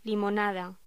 Locución: Limonada
voz